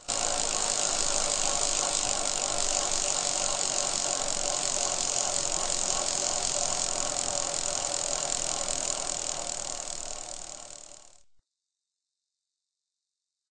bike_wheel_spin.ogg